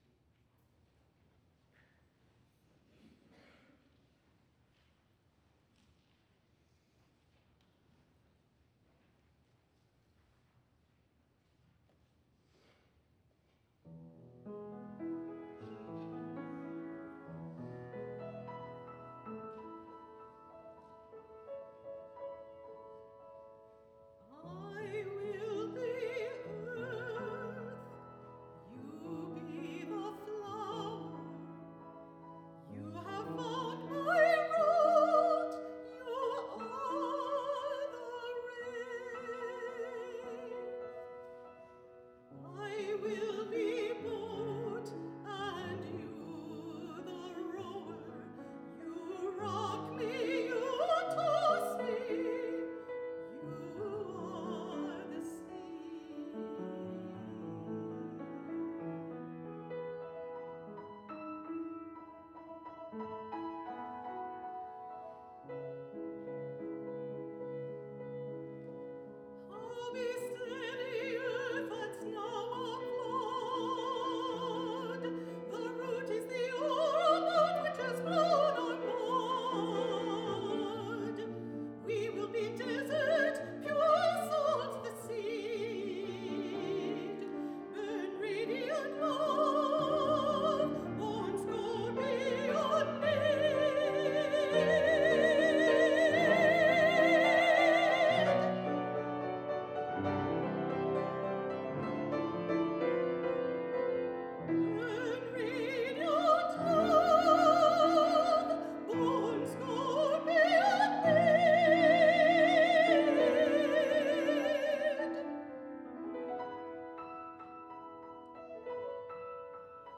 for High Voice and Piano (1993)
soprano
piano.